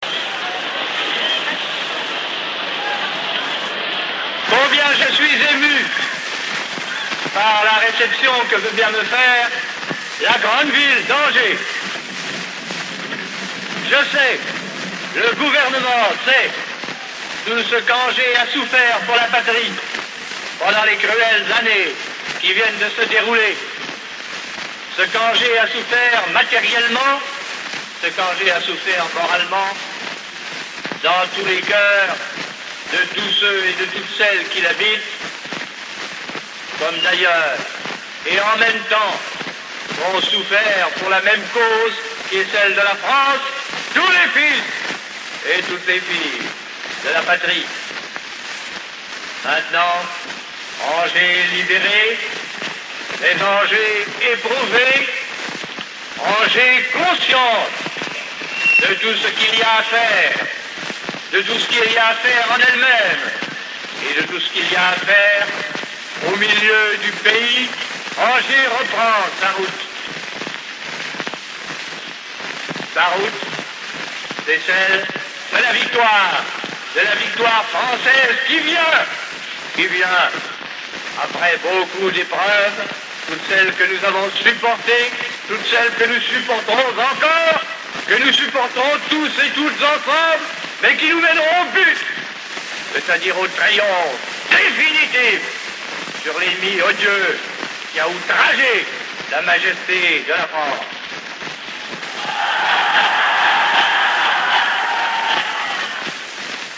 CD-audio réalisé par les Archives départementales de Maine-et-Loire à partir d'un disque vinyl d'époque comportant notamment le discours prononcé par le général de Gaulle lors de sa venue à Angers le 14 janvier 1945 suivi de la Marseille chantée par le général de Gaulle et les Angevins (Angers, 2003, 5 mn et 41 s.).